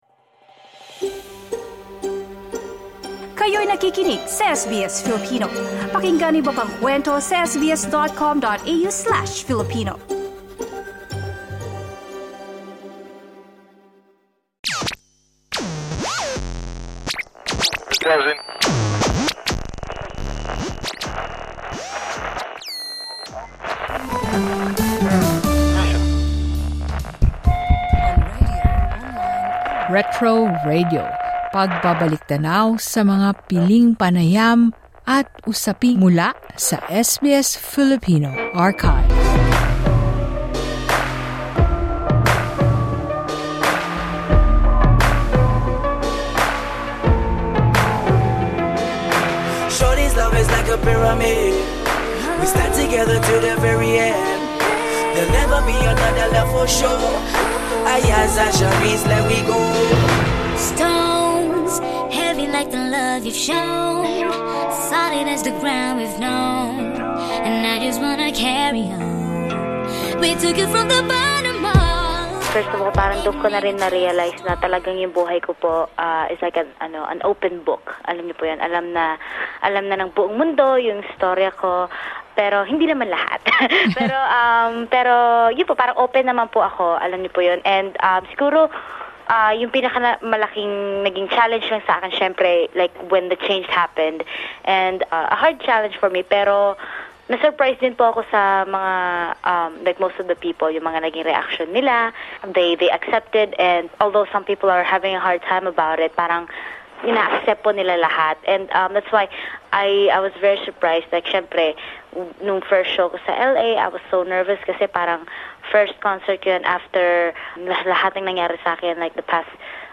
Retro Radio: As SBS celebrates 50 years of broadcasting, we look back at some of the interviews from the SBS Filipino archives. Listen to our 2014 interview with Jake Zyrus.